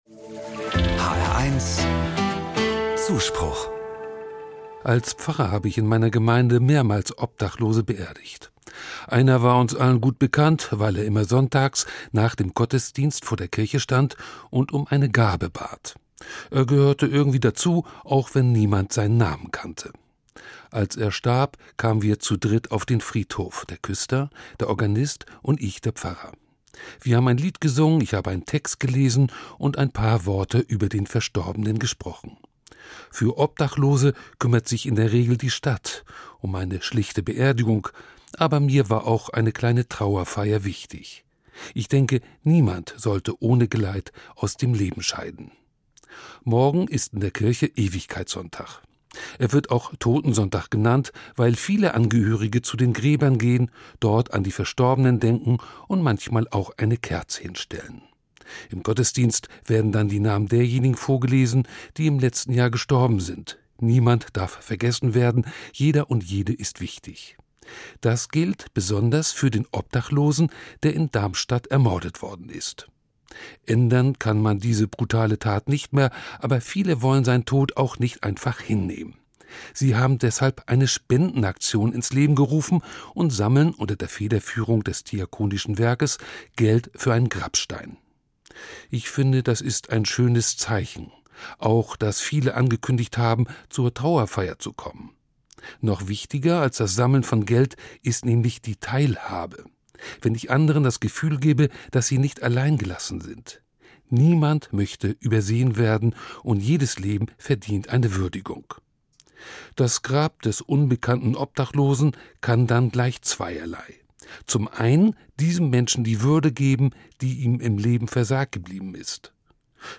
Evangelischer Pfarrer, Kassel